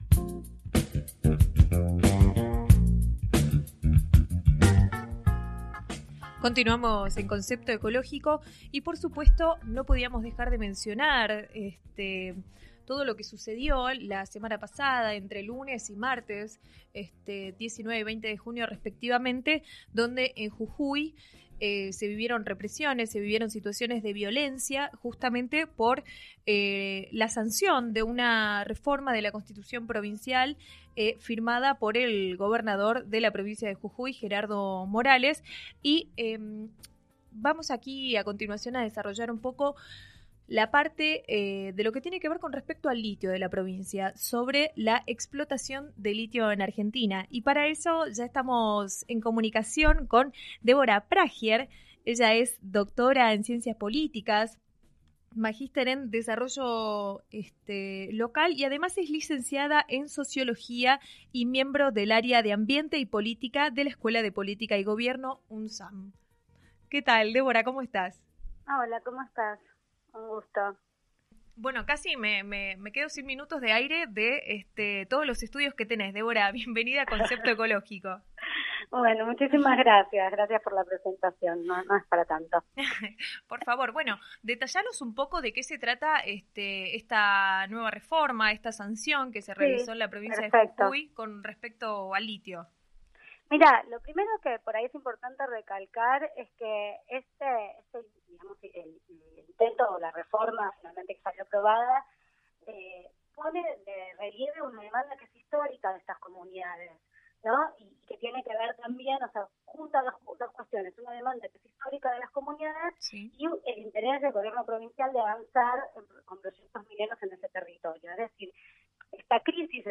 El programa es transmitido por 12 radios a lo largo del país y versa sobre temas vinculados al ambiente y la ecología.